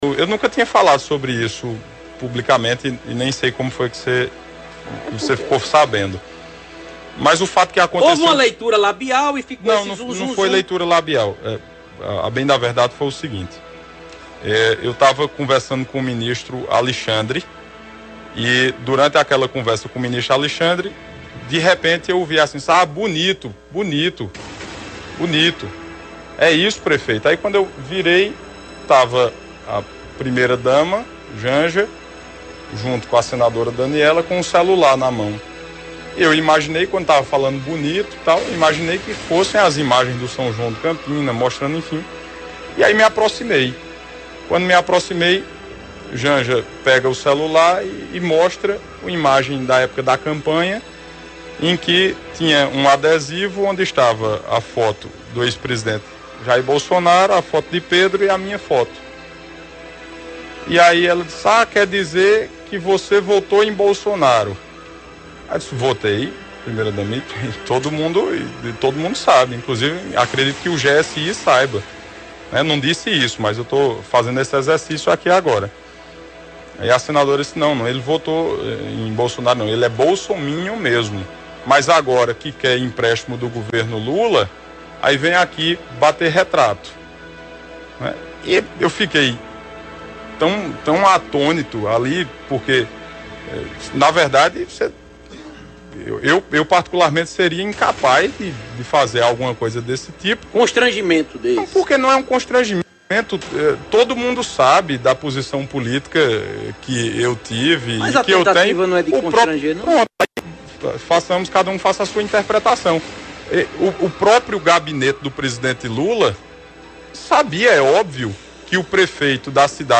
O prefeito Bruno Cunha Lima, de Campina Grande, concedeu entrevista a imprensa nesta terça-feira (25) e revelou que a senadora Daniella Ribeiro (Progressistas) teria tentado prejudicar sua imagem junto ao governo Lula (PT) e, consequentemente, barrar emendas para Campina.